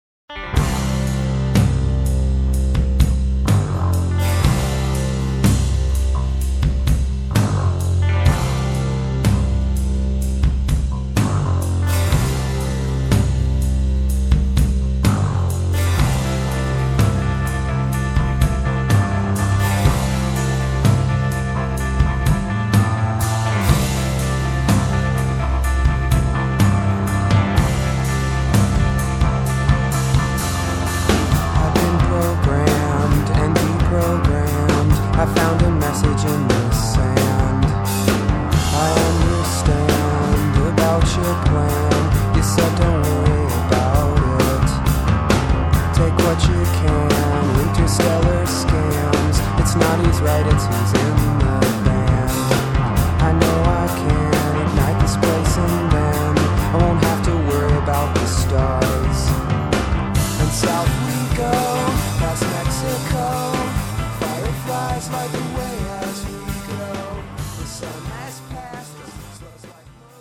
Recorded at Pachyderm Studios, Cannon Falls, MN